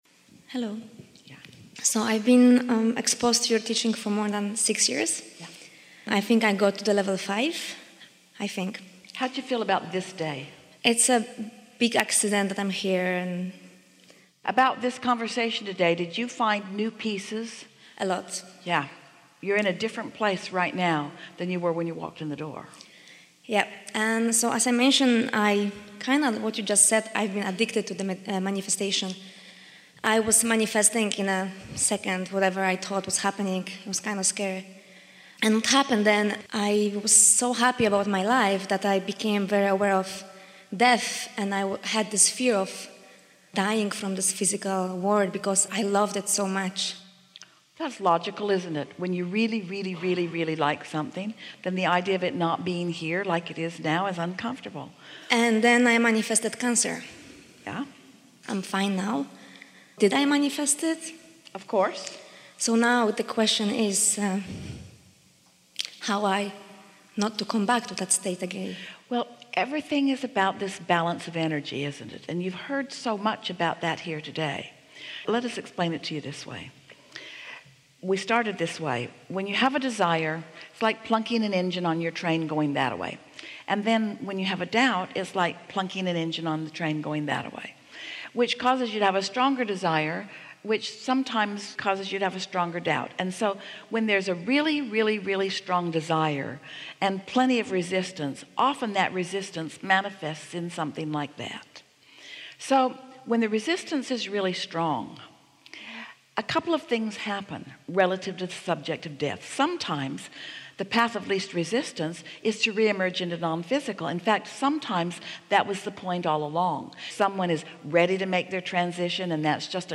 Then I stumbled straight into this segment from an A-H workshop in San Antonio recently which addresses this topic and here it is: